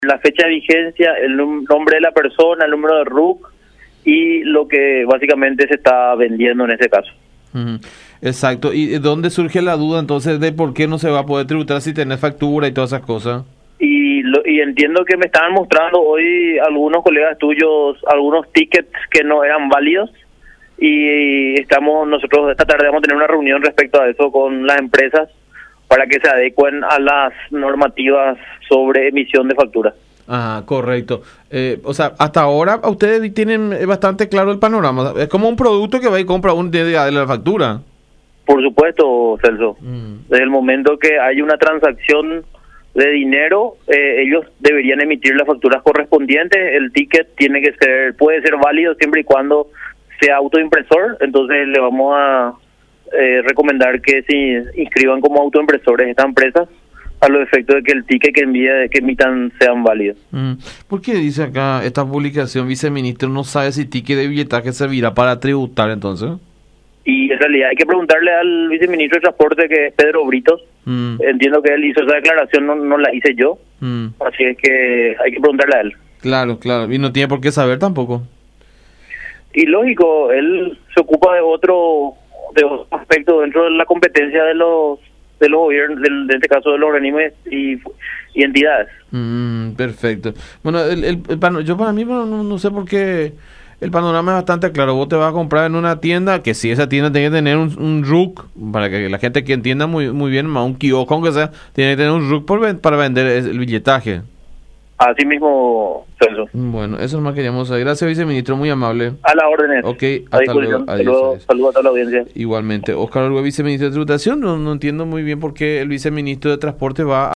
El viceministro de Tributación, Óscar Orué, aclaró que el boleto emitido por el nuevo sistema de billetaje electrónico sí sirve para tributar, pero que debe poseer ciertos requisitos.